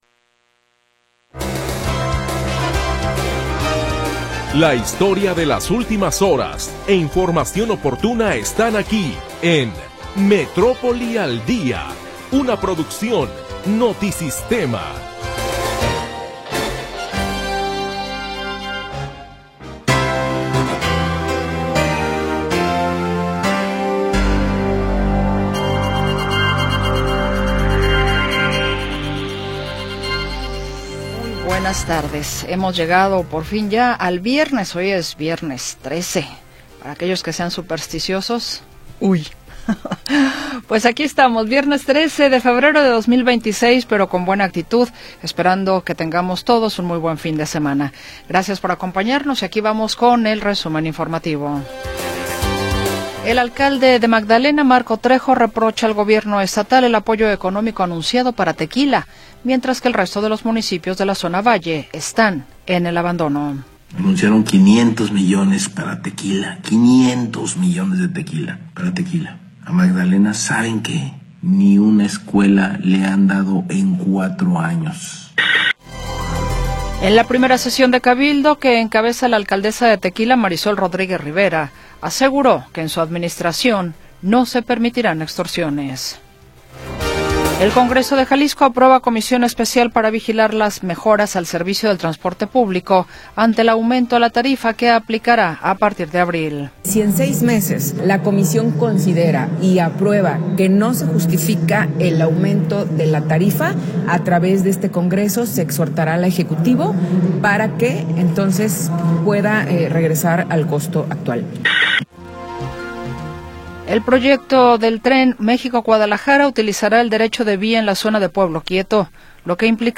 Análisis, comentarios y entrevistas